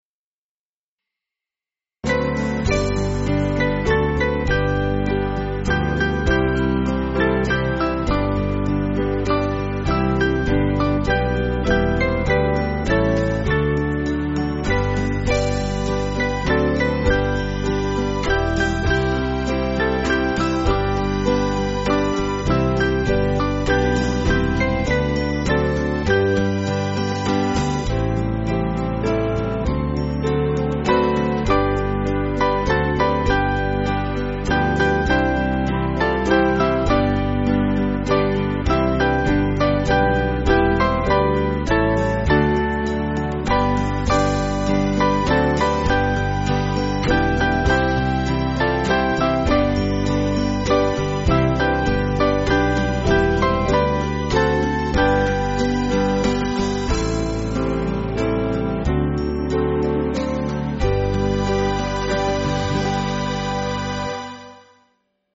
Small Band
(CM)   2/G